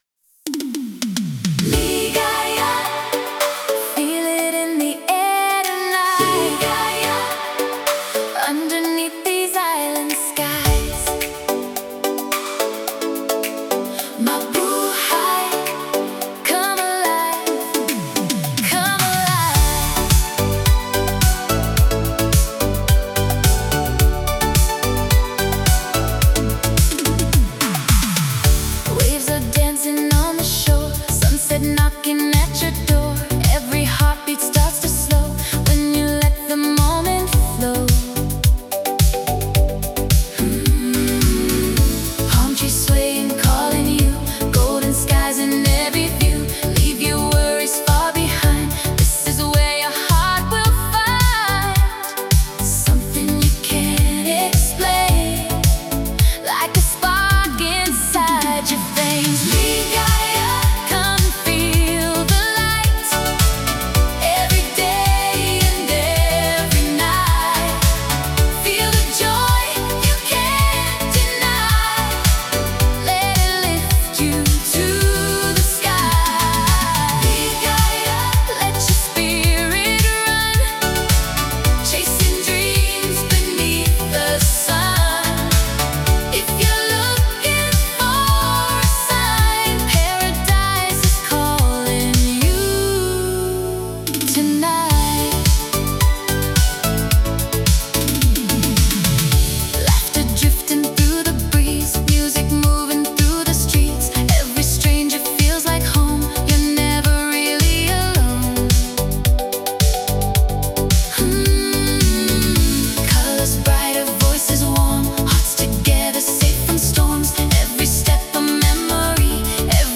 “Ligaya” is an uplifting tropical pop track with 1980s-inspired warmth, rich female harmonies, and a nostalgic beach sunset vibe celebrating joy, connection, and paradise.